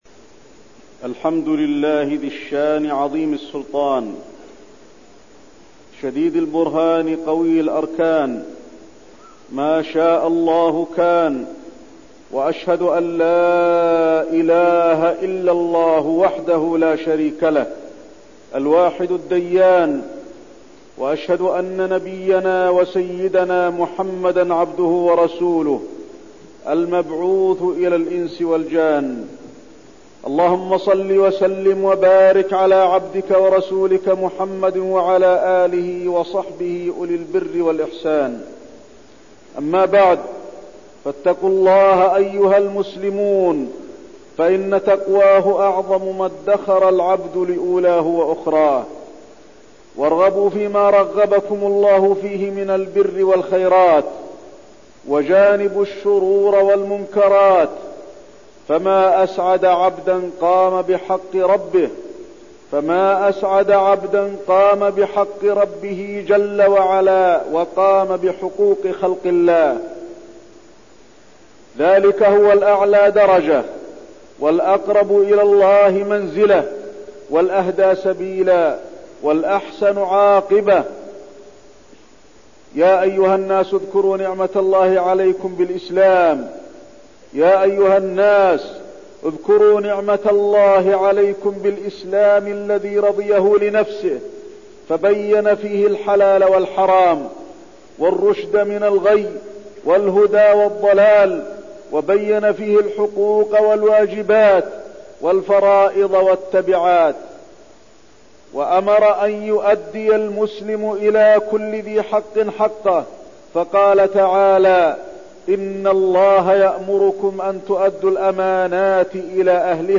تاريخ النشر ١٢ محرم ١٤١١ هـ المكان: المسجد النبوي الشيخ: فضيلة الشيخ د. علي بن عبدالرحمن الحذيفي فضيلة الشيخ د. علي بن عبدالرحمن الحذيفي الأخوة الإسلامية